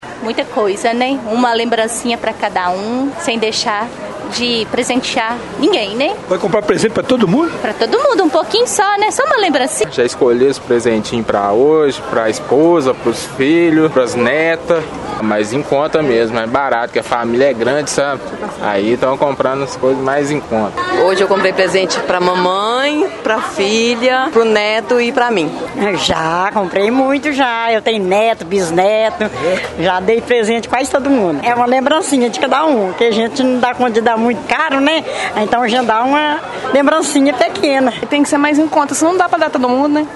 Nossa equipe conversou com alguns consumidores e percebe que o Natal será, mais uma vez, marcado pelas lembrancinhas.
Consumidores